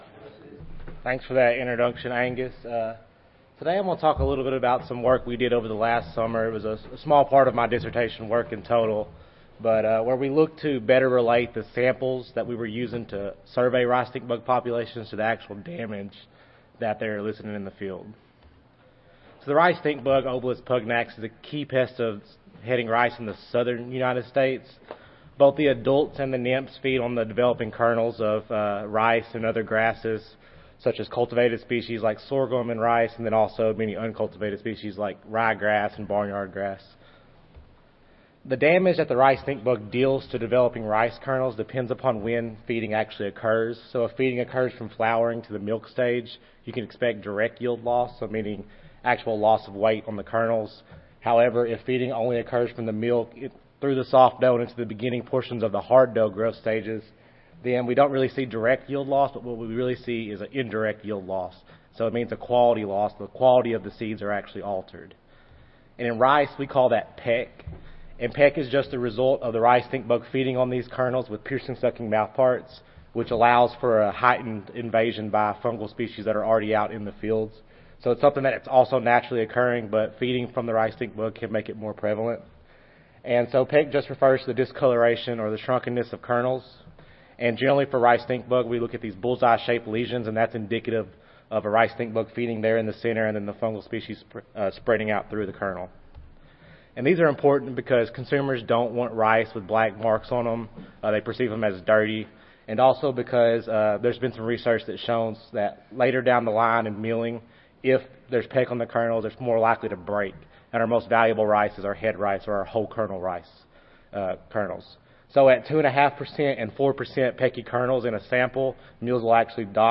Recorded Presentation The objective of this study was to relate direct and indirect yield loss by the rice stink bug to a defined sampling area using uncaged trials.